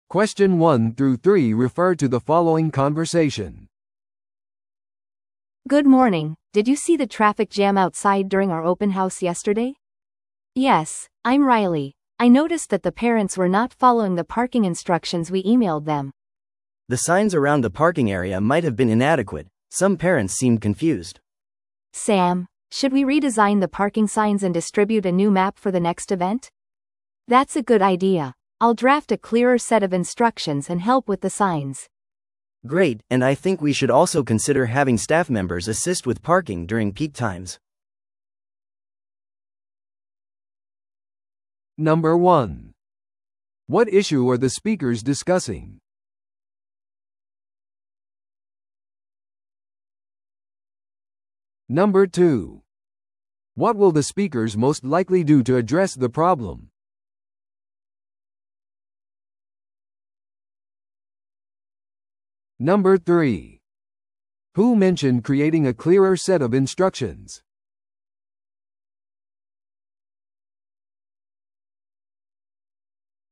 B: 会話で最初に話した話者。